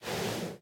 Minecraft Version Minecraft Version latest Latest Release | Latest Snapshot latest / assets / minecraft / sounds / mob / horse / breathe2.ogg Compare With Compare With Latest Release | Latest Snapshot
breathe2.ogg